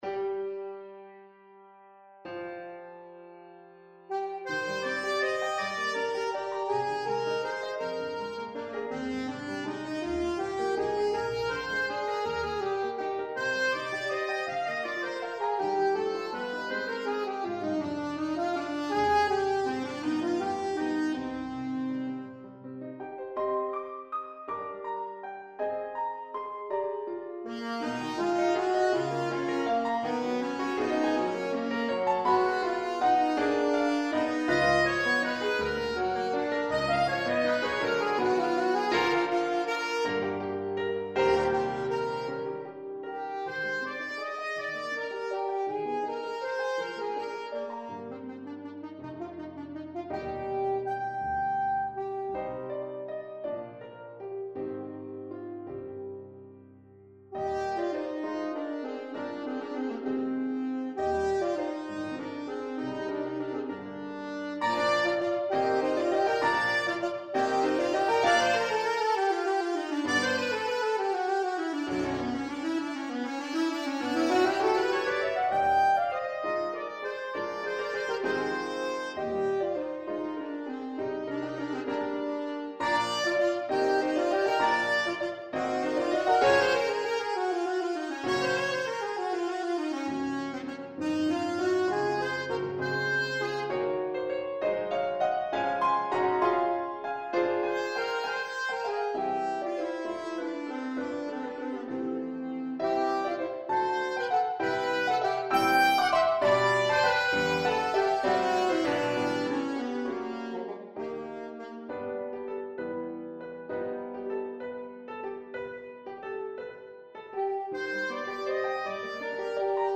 Alto Saxophone
6/8 (View more 6/8 Music)
Classical (View more Classical Saxophone Music)